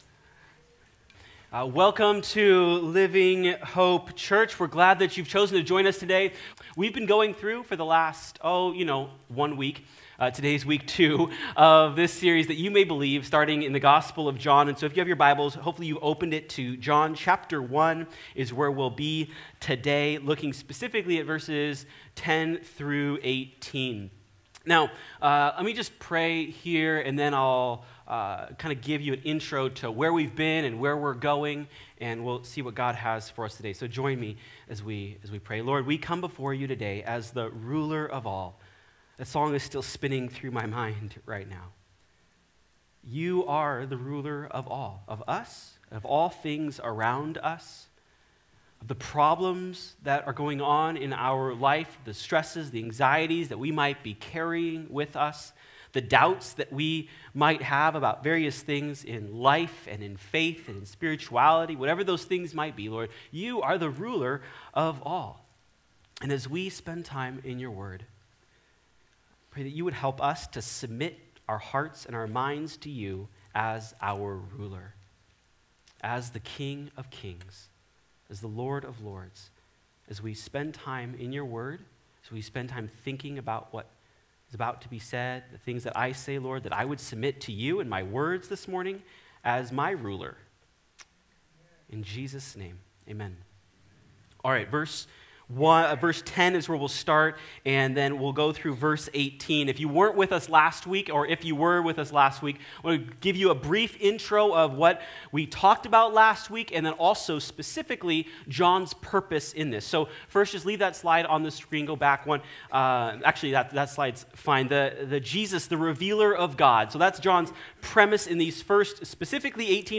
So, what does John teach us about Jesus? Sermon